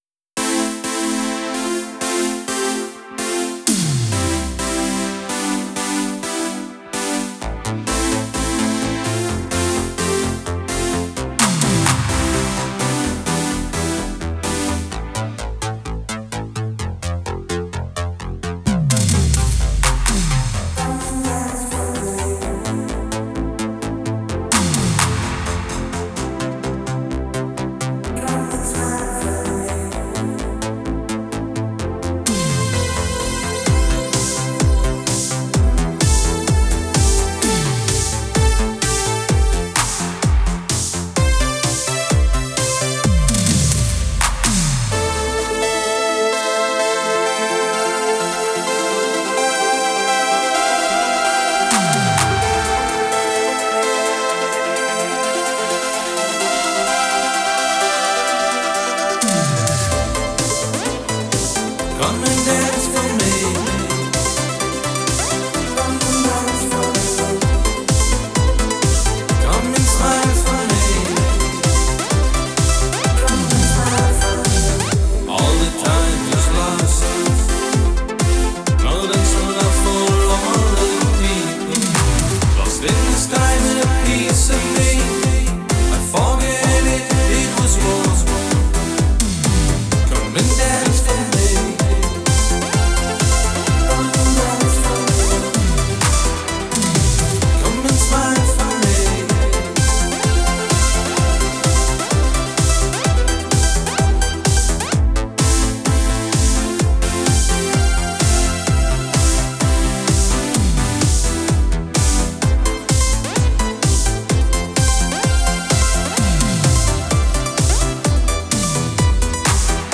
Synthpop · Synthwave · CD & Vinyl
Extended Version · WAV & MP3